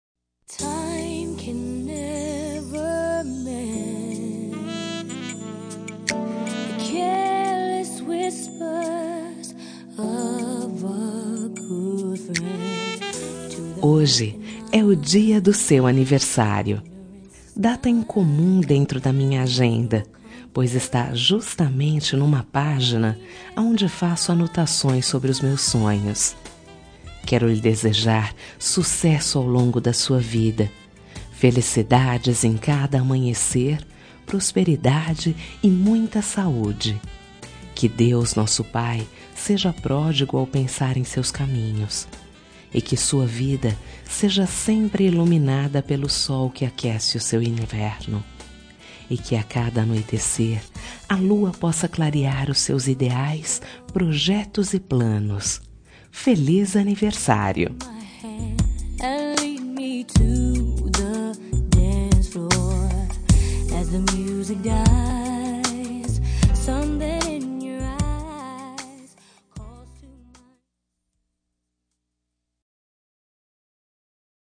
Telemensagem Aniversário de Paquera -Voz Feminina – Cód: 1241